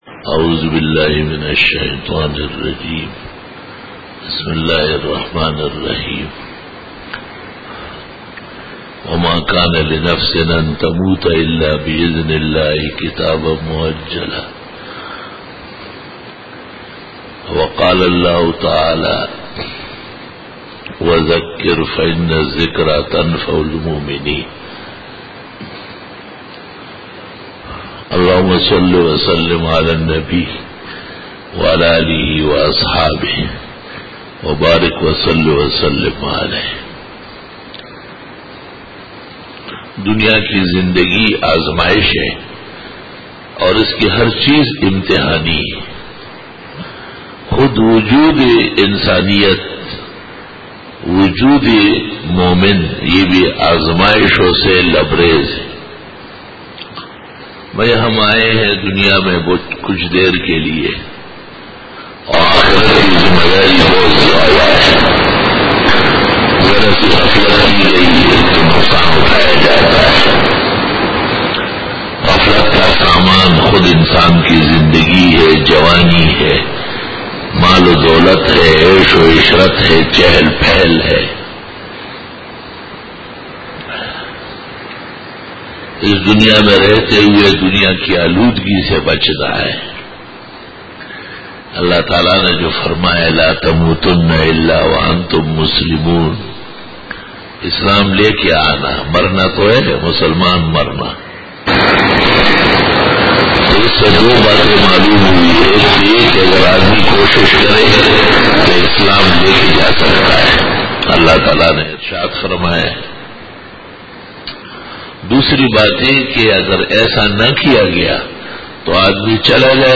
19_BAYAN E JUMA TUL MUBARAK 09-MAY-2014
بیان جمعۃ المبارک 09 مئی 2014